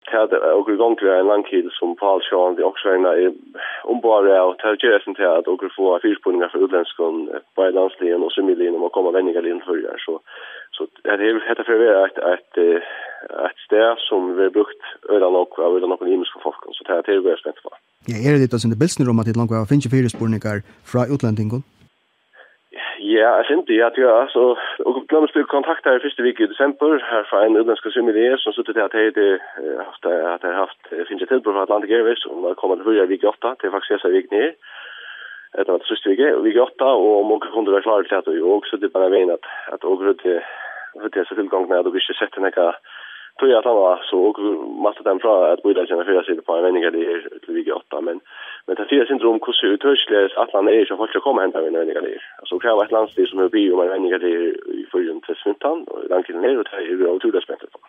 Brot úr tíðindasendingini hjá Kringvarpi Føroya hin 21. februar 2015